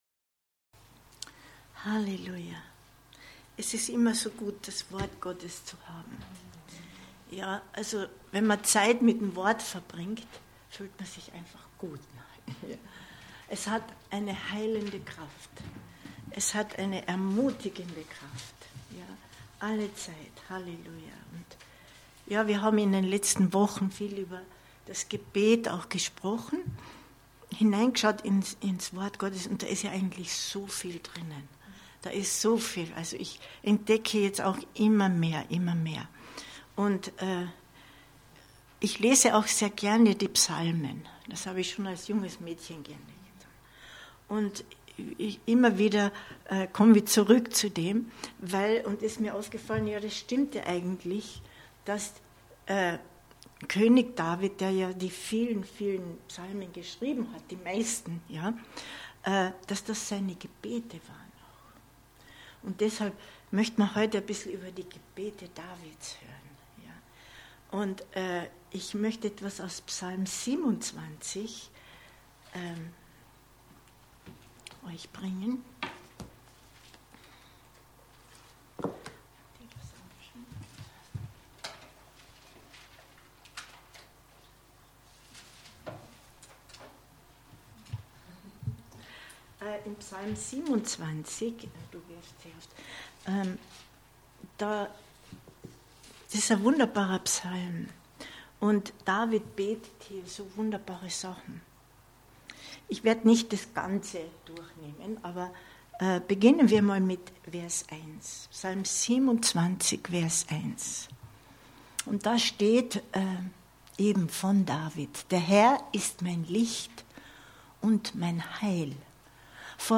Aufnahme des Bibelabends vom Mittwoch, 21.09.2022